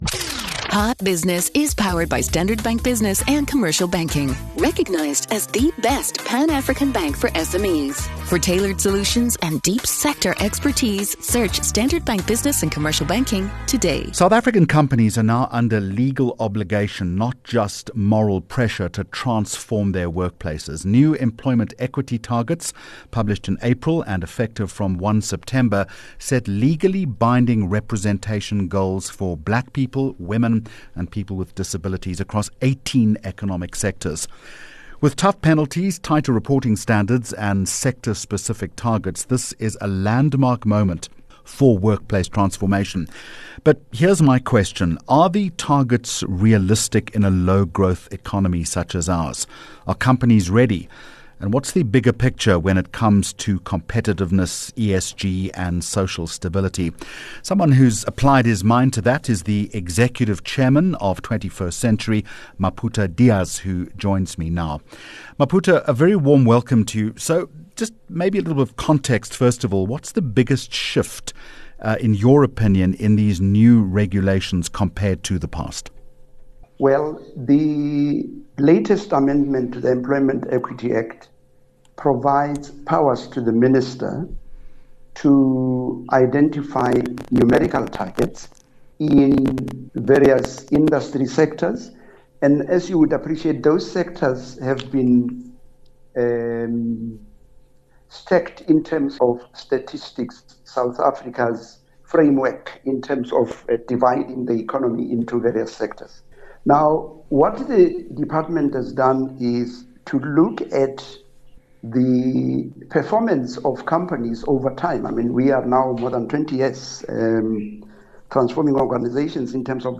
24 Jul Hot Business Interview